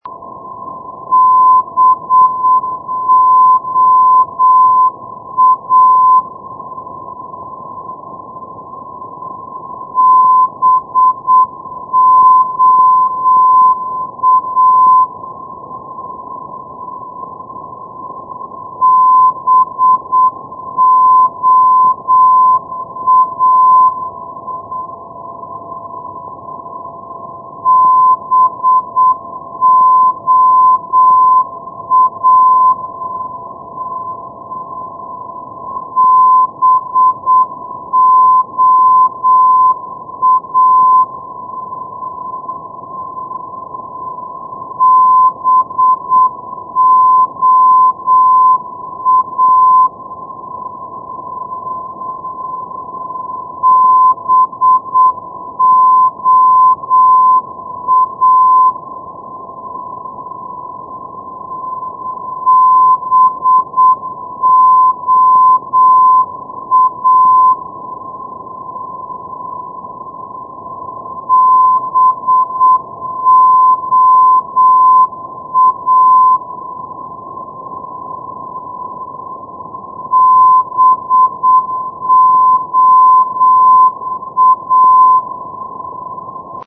negative keying due to modulation
Morse Telegraphy